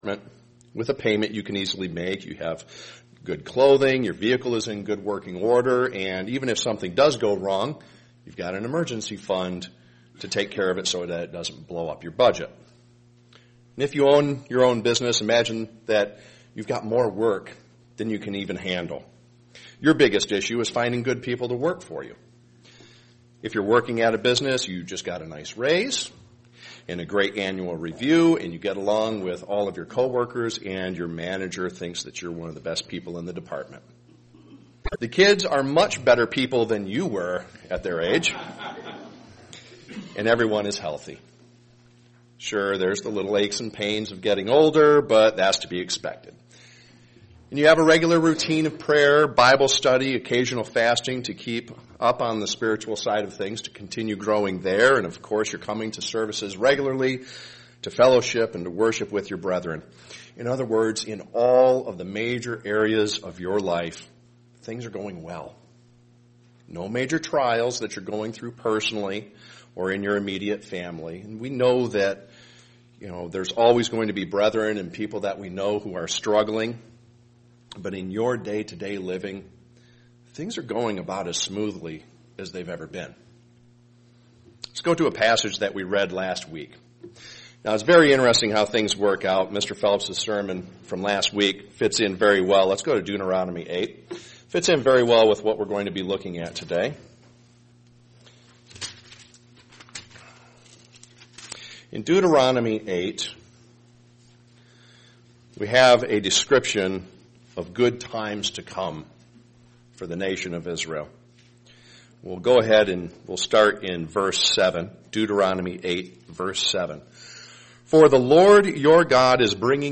Print With all the distractions in life, we must stay focused on what is most important. sermon Studying the bible?